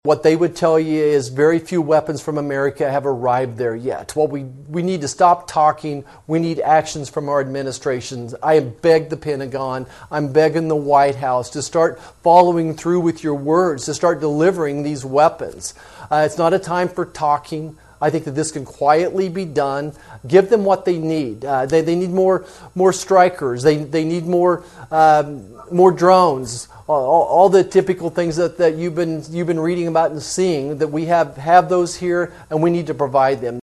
In a special Zoom meeting with media Monday, Marshall asked the Biden administration to implement sanctions now instead of this upcoming summer and he asked other nations to “stop doing business with Russia.”